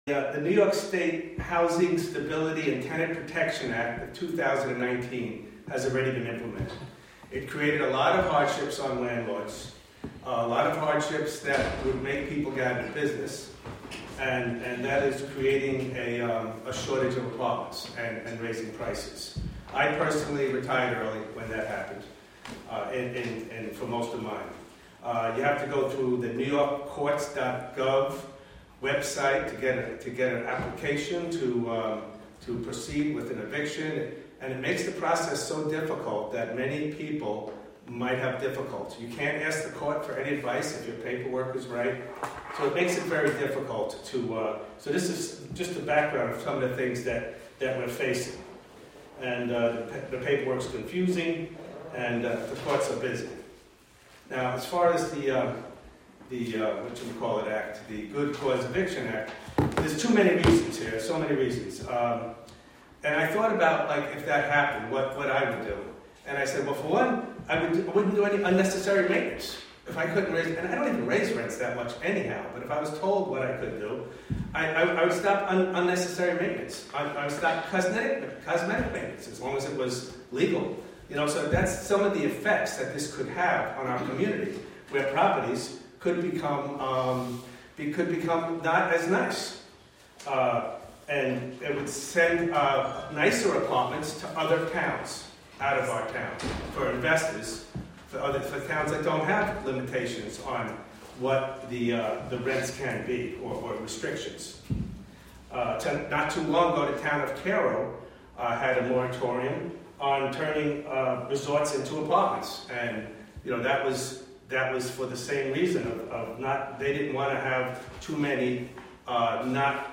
Live from the Town of Catskill: May 21, 2025 Catskill Town Board Meeting (Audio)